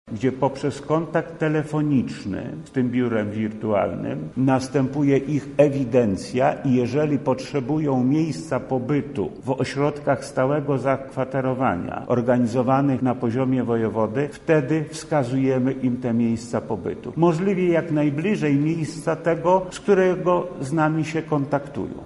Dla osób, które nie udały się do przygranicznego punktu recepcyjnego, na poziomie urzędu wojewódzkiego funkcjonuje system wirtualny – mówi wojewoda lubelski Lech Sprawka: